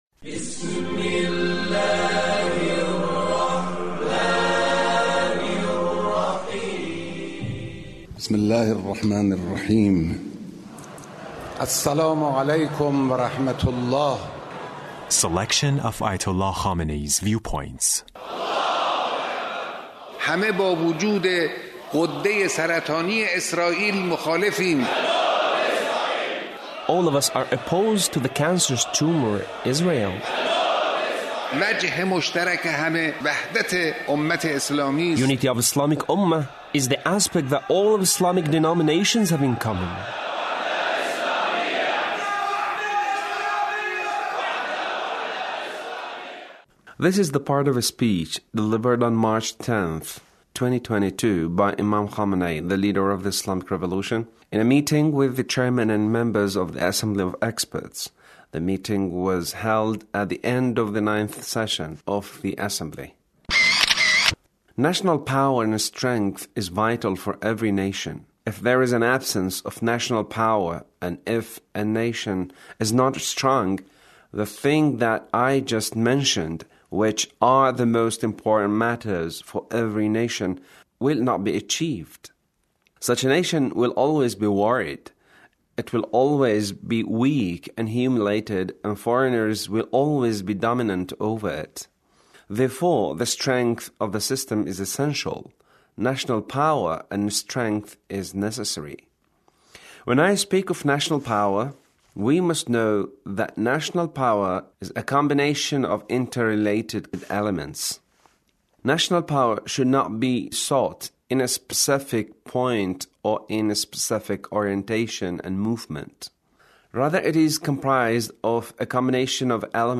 The Leader's speech on Assembly of Experts